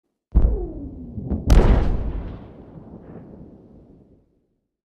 Звуки дирижабля
Взрыв в далеком небе